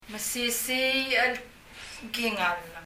strong・healthy・fine 強い・頑丈な mesisiich [mɛsi:si ?] mesisiich は ak mesisiich で I’m fine.